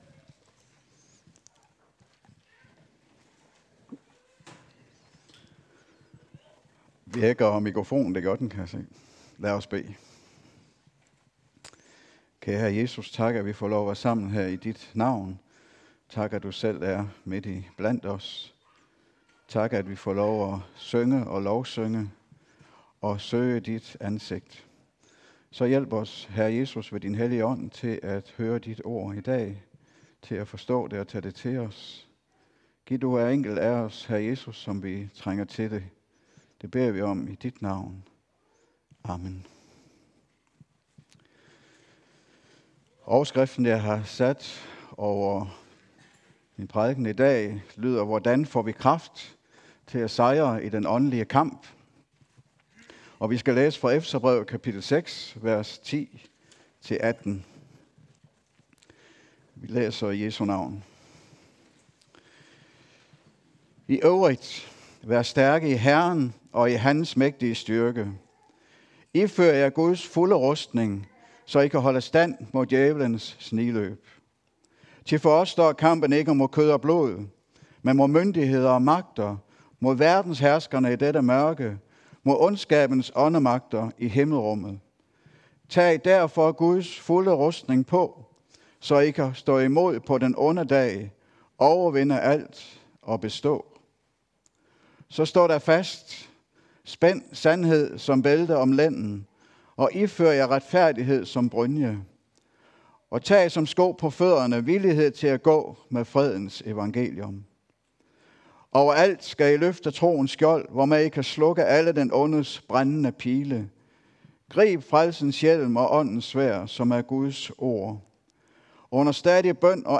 Lyt til prædikener optaget i Kirken Ved Søerne